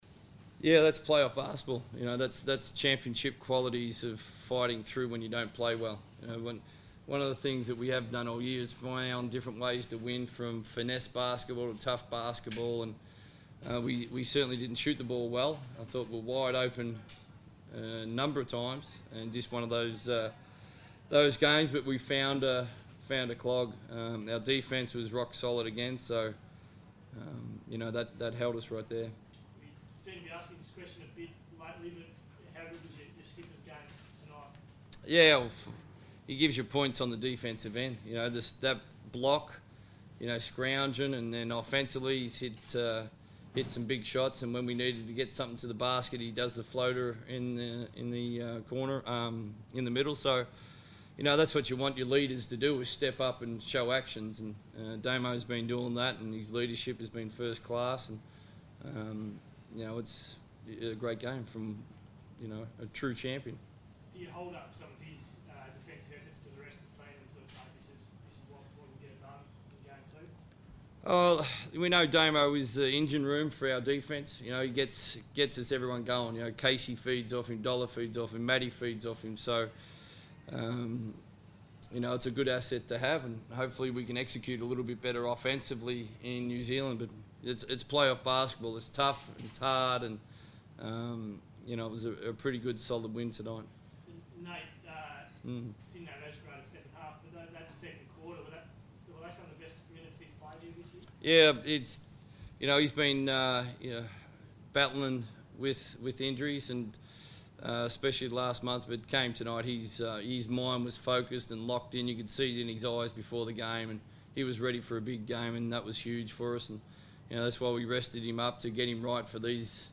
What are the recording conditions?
speak to the media following the Wildcats win over the NZ Breakers.